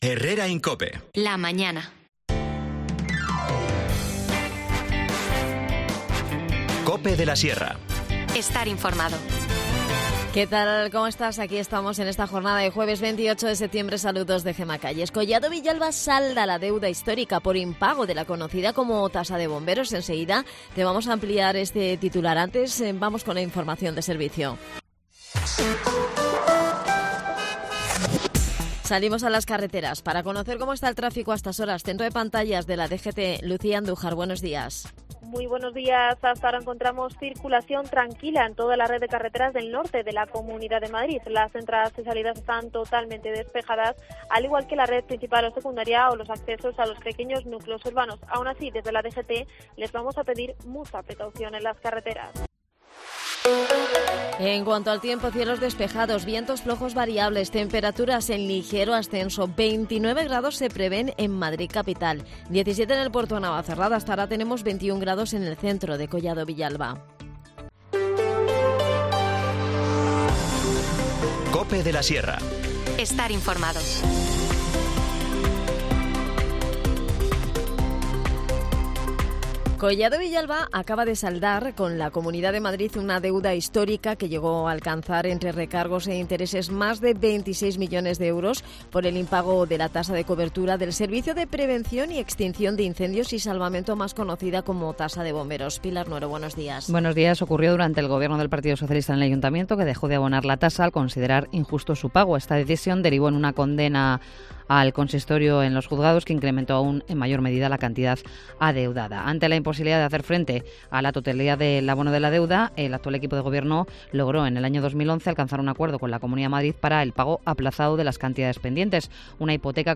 San Miguel, San Gabriel y San Rafael ya aparecen nombrados en la Biblia, llevando a cabo misiones muy importantes encomendadas por Dios. Charlamos sobre el tema.